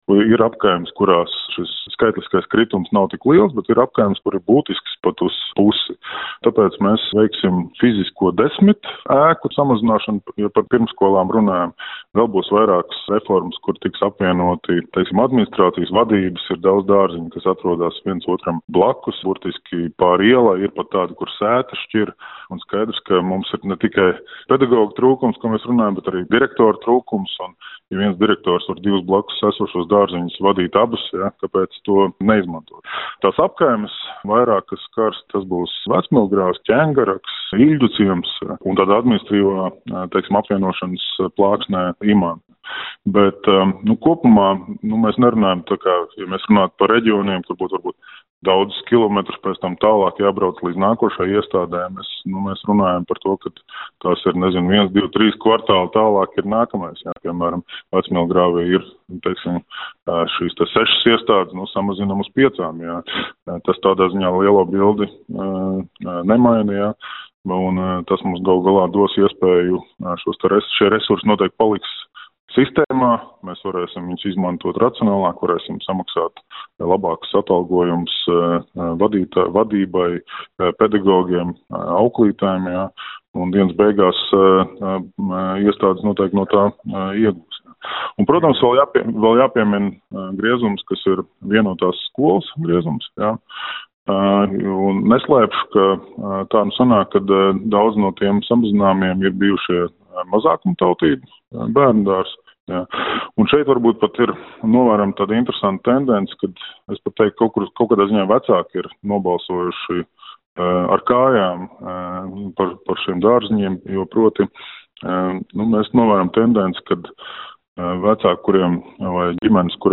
Tāpēc Rīgas pašvaldība iecerējusi vairākas pirmsskolas izglītības iestādes slēgt vai reorganizēt, to intervijā Skonto mediju grupai (SMG) pastastīja Rīgas vicemērs Vilnis Ķirsis.
Rīgas vicemērs Vilnis Ķirsis